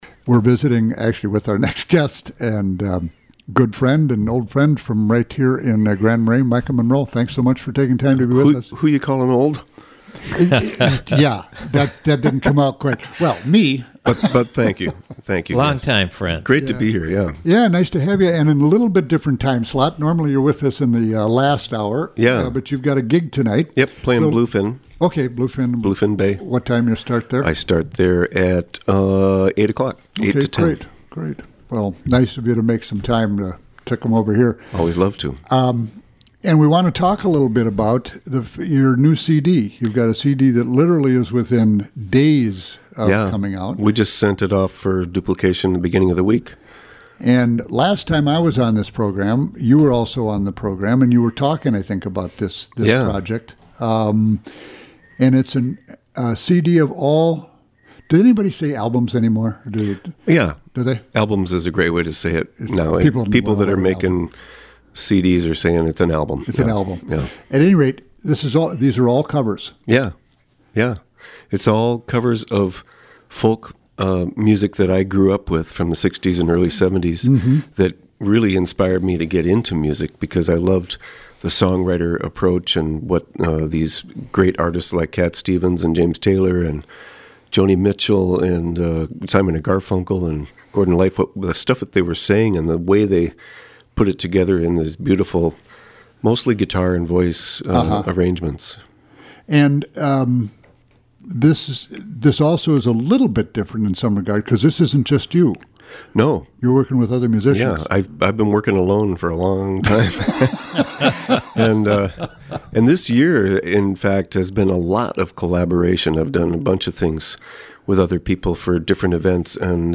Program: Live Music Archive The Roadhouse